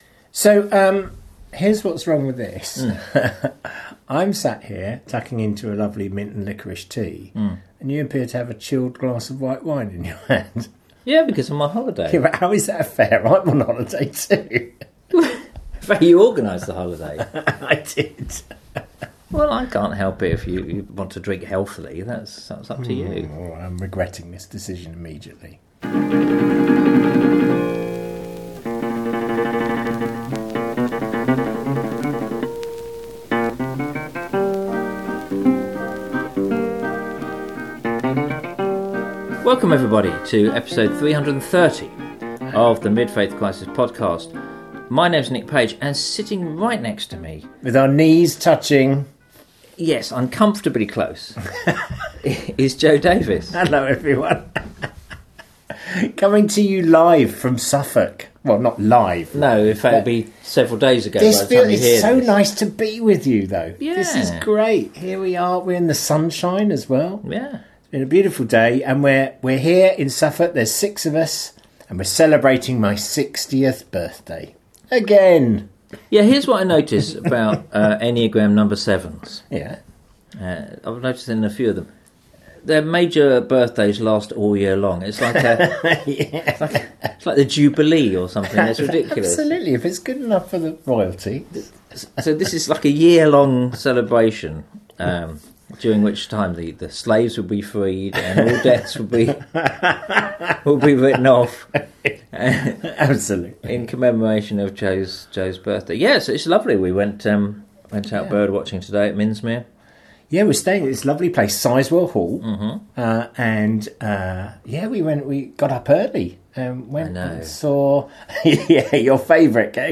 In an episode recorded actually together in the same room, we talk about getting older.